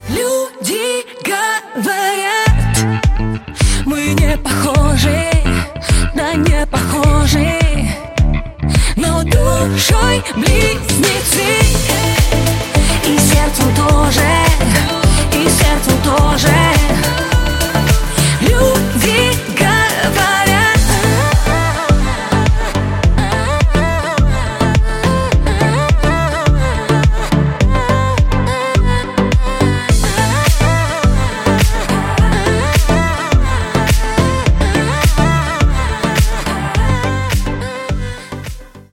эстрада